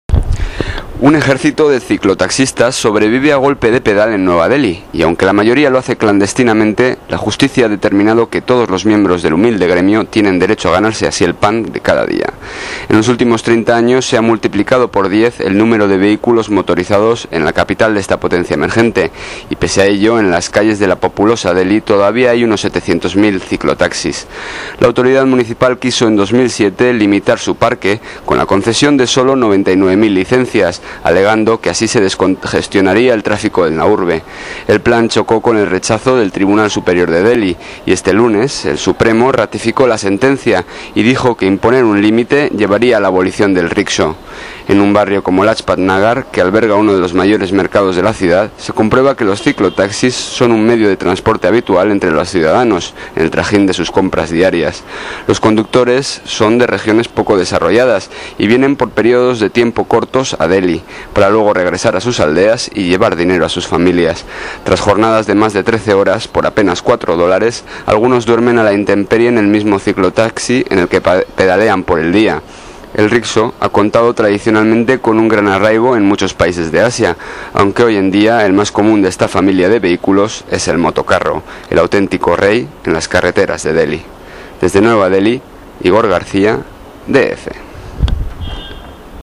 Muestra de las crónicas de radio hechas con la Agencia EFE en el sur de Asia entre 2008 y 2013.